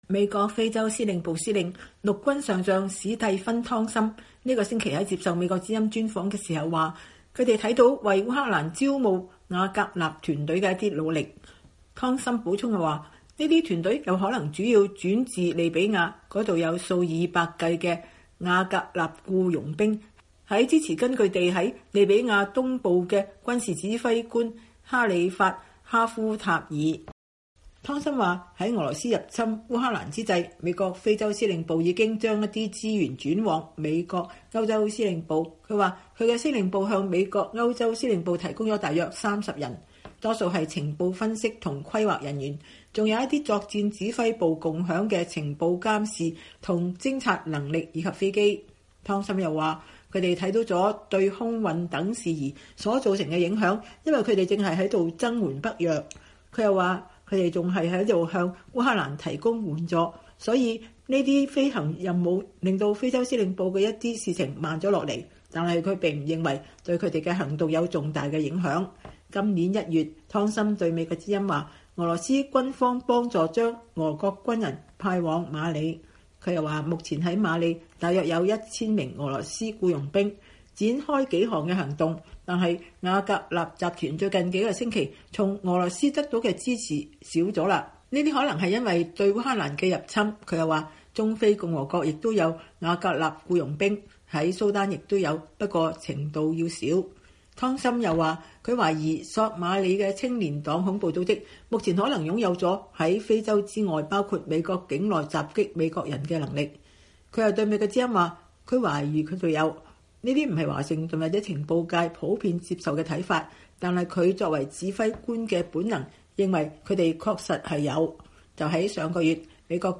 專訪美軍司令：瓦格納集團在非洲為侵烏俄軍招募僱傭兵 赤道幾內亞無計劃建中國軍事基地